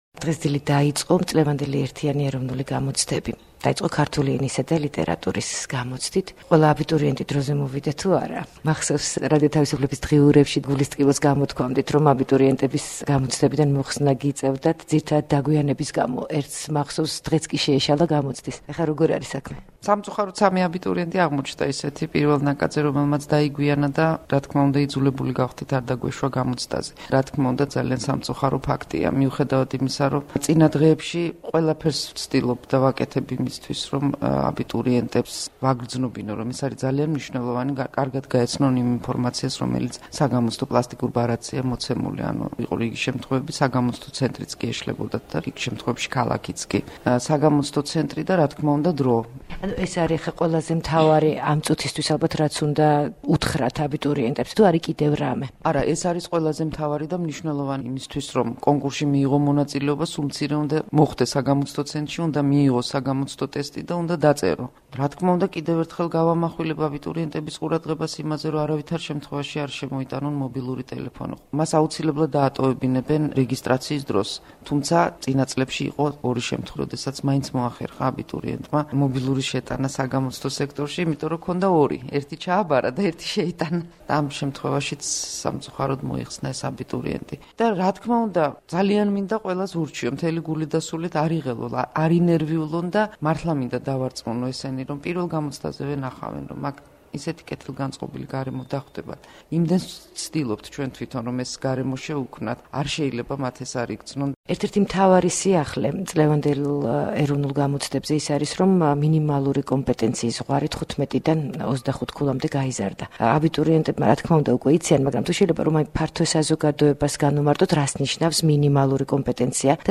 interviu 02.07